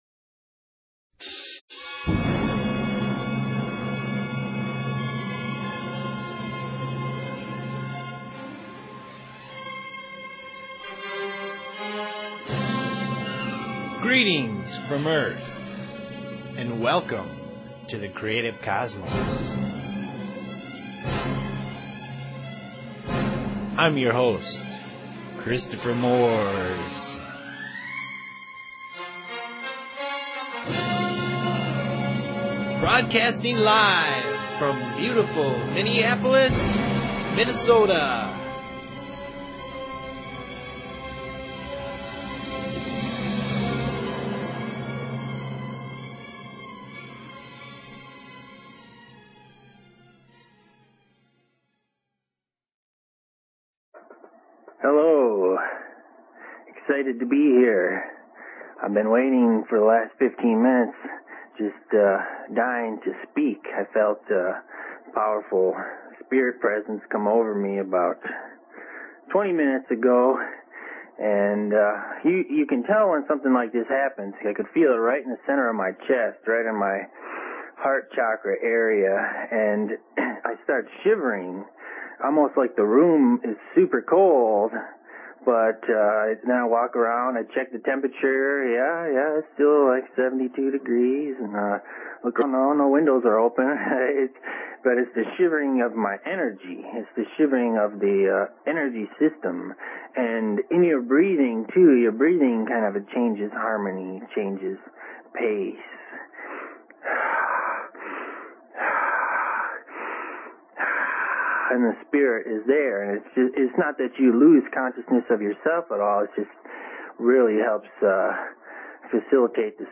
Zen, Chakras, Dreams, Astral Plane, Aliens, Spirit Communication, Past Lives, and Soul Awakening are all in play. There are no boundaries as the conversation goes where it needs to in the moment.